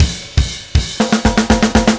Power Pop Punk Drums 02 Fill A.wav